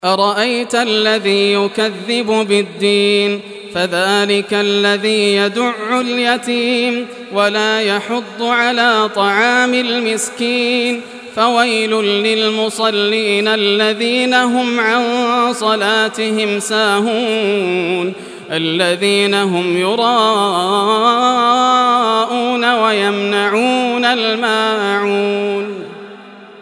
Surah Maun Recitation by Yasser al Dosari
Surah Maun, listen or play online mp3 tilawat / recitation in Arabic in the beautiful voice of Sheikh Yasser al Dosari.
107-surah-maun.mp3